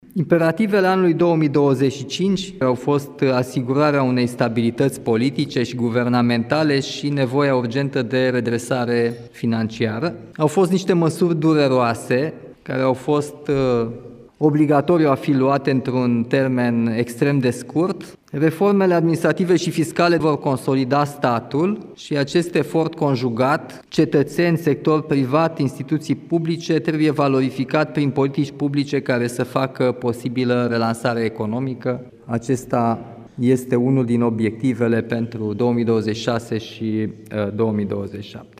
Declarația a fost făcută în cadrul întâlnirii anuale cu șefii misiunilor diplomatice din România.